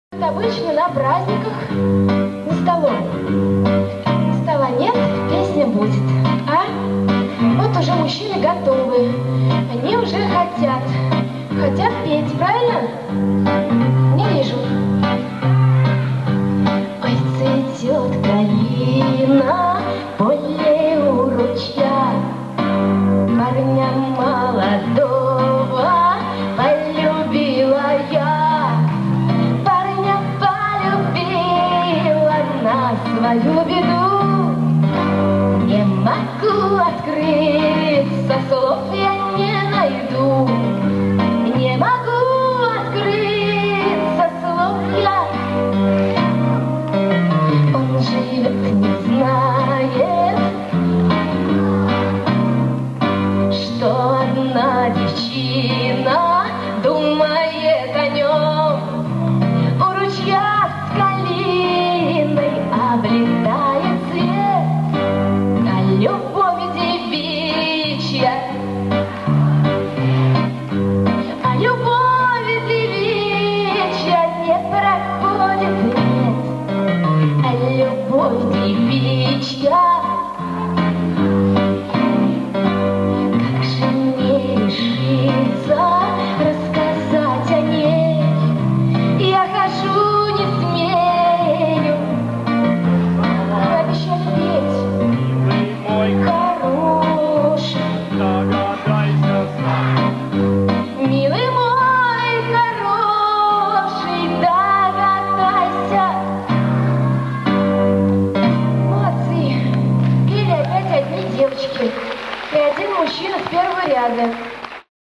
народную песню